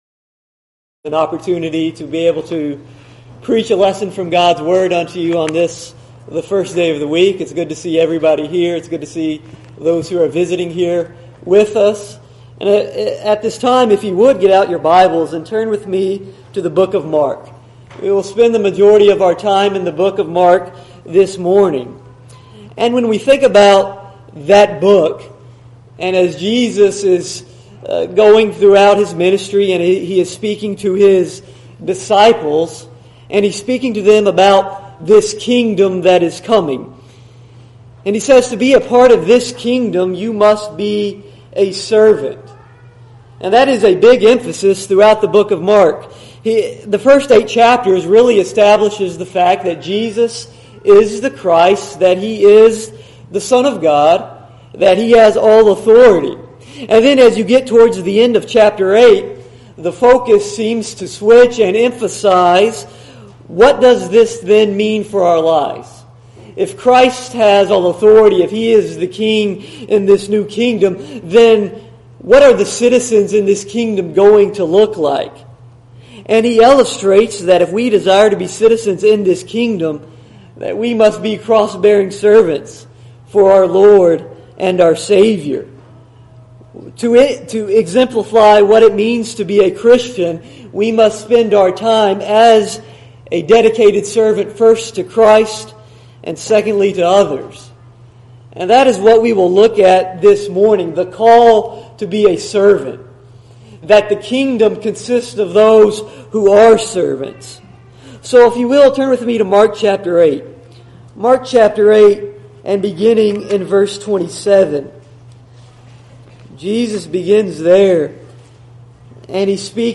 Sermon: A Kingdom of Servants